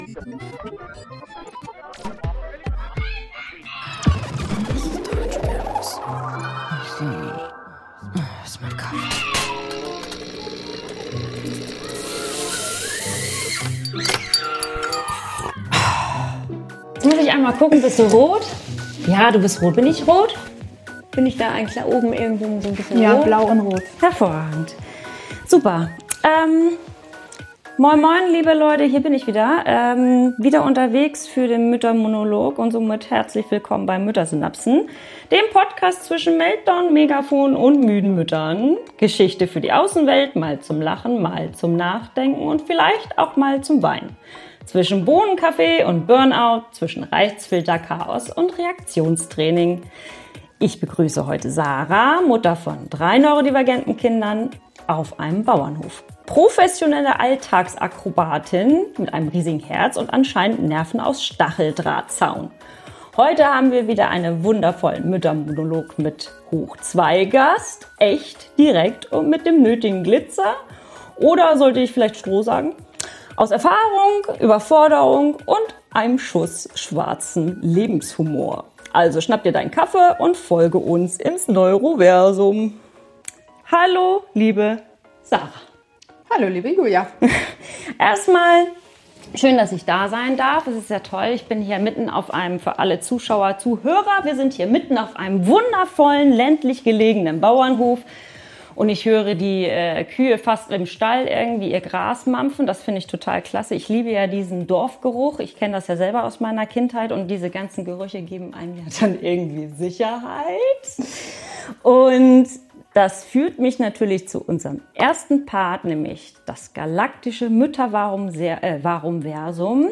Ein ehrliches, herzliches Gespräch über das frei, frech und wild sein, mit all den kleinen und großen Macken, die dazugehören.